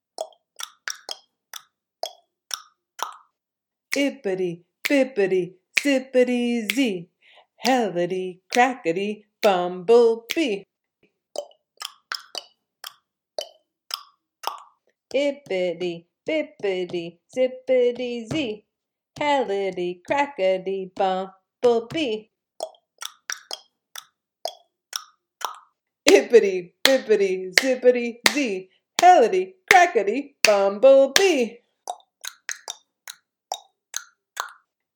You can hear 3 renditions of this rhyme in the recording below, all at different tempos.